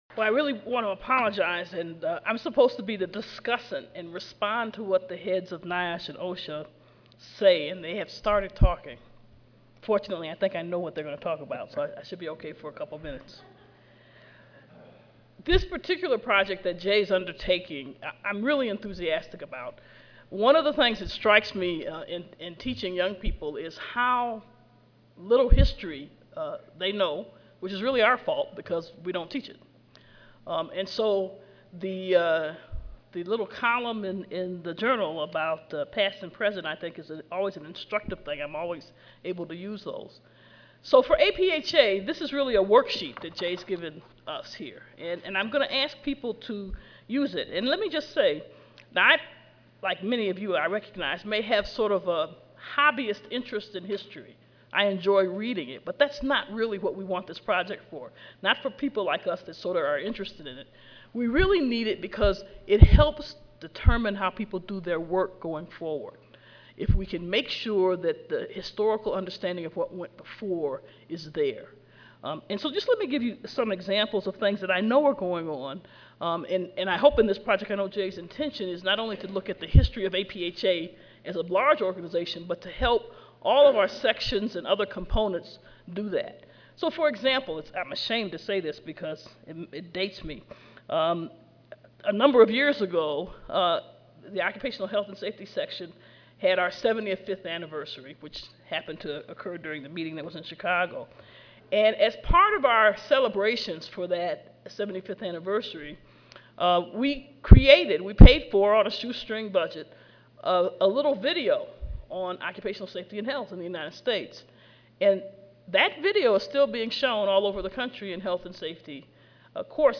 A panel of discussants will present the project and significance, audience discussion will follow.